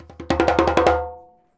100DJEMB08.wav